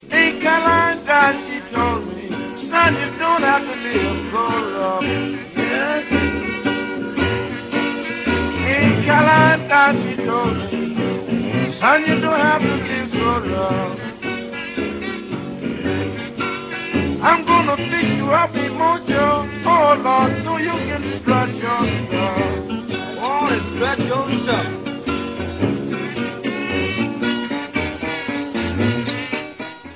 вокал, гитара
казу
джаг